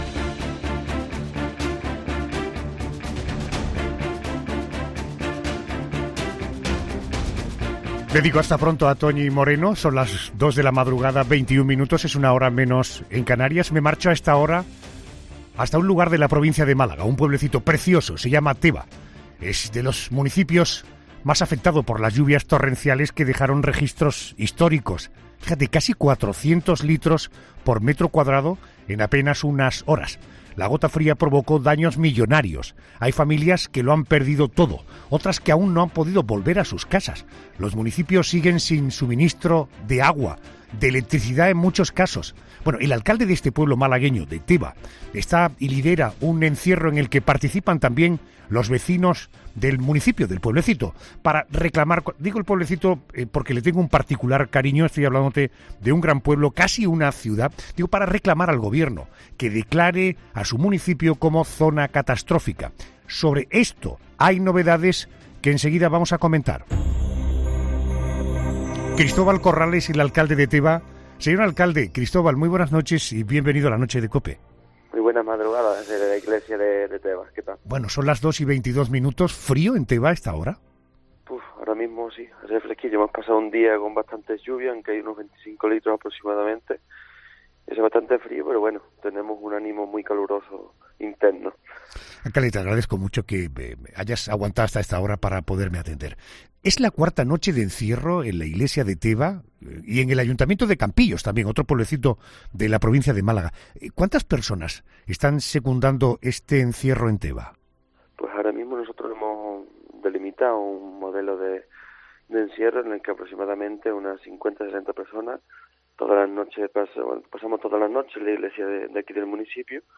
en una entrevista en 'La Noche de COPE'